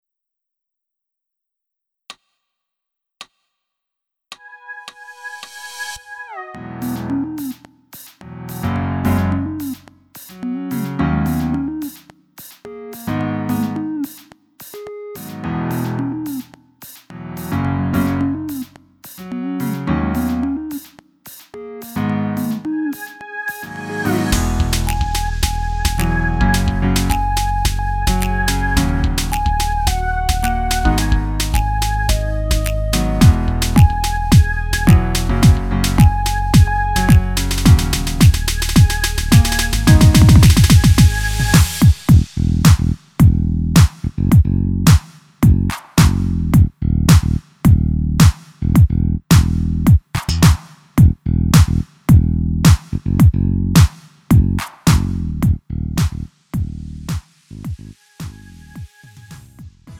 음정 -1키 3:08
장르 구분 Lite MR